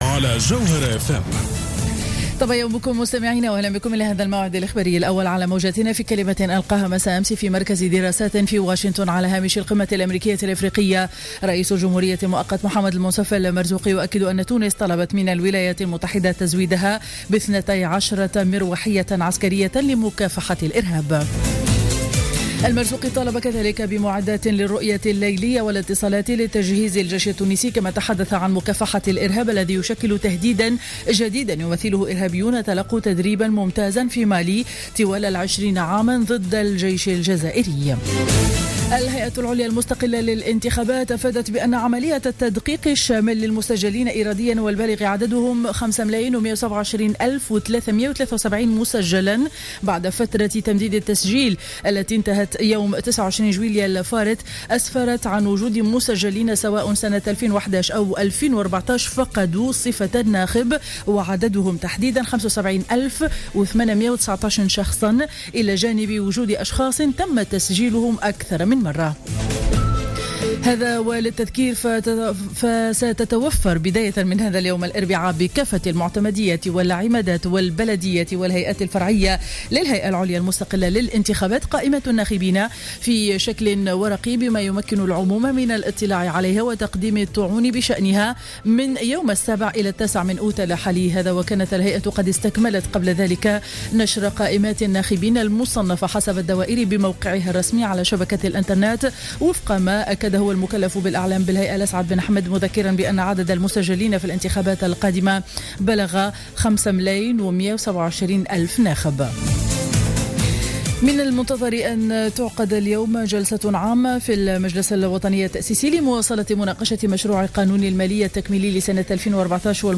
نشرة أخبار السابعة صباحا ليوم الاربعاء06-08-14